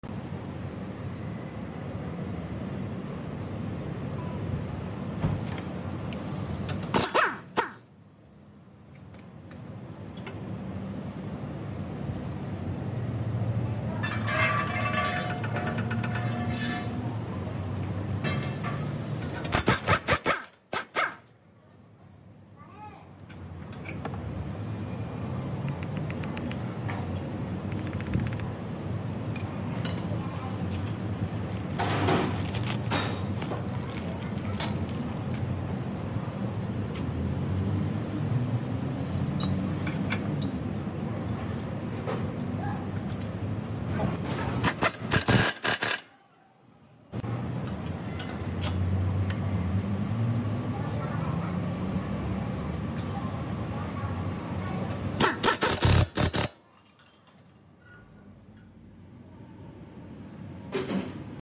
stabilizer link sound fixed change